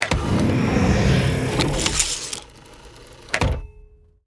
DoorClose4.wav